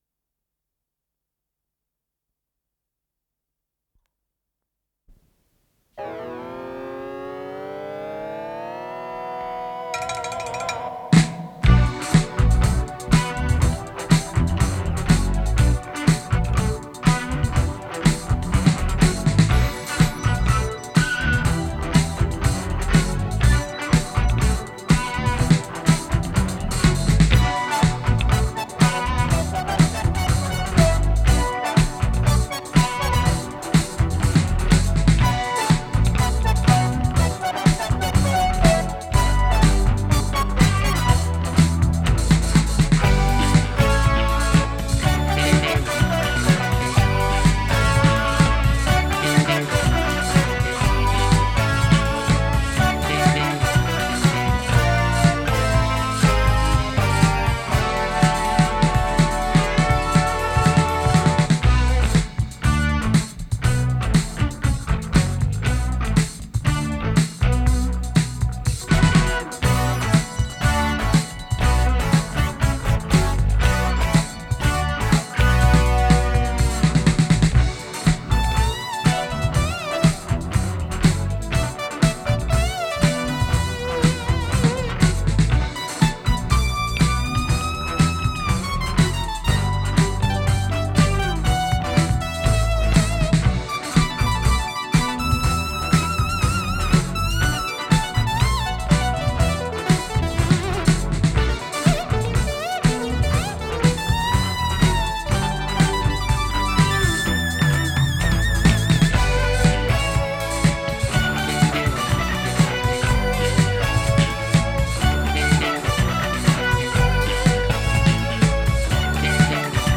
с профессиональной магнитной ленты
соло-гитара
бас-гитара
клавишные
ударные
ВариантДубль моно